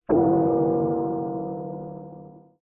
dead.wav